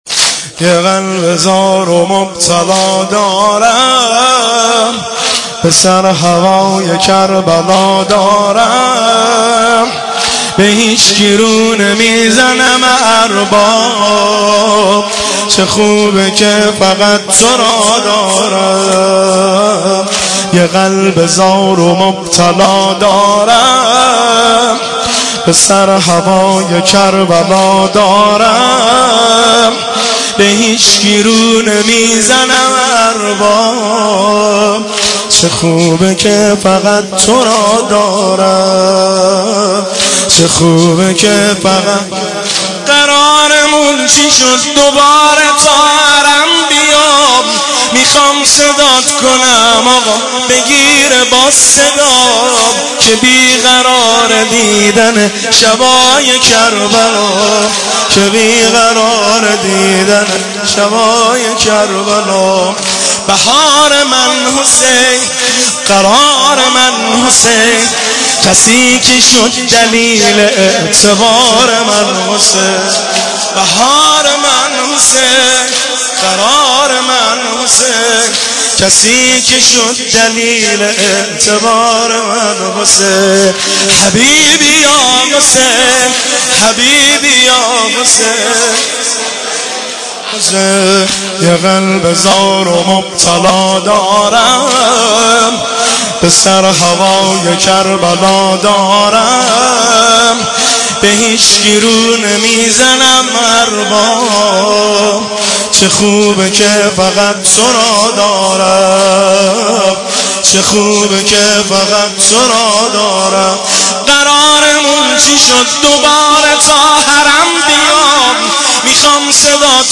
مداحی
شب دوم ایام مسلمیه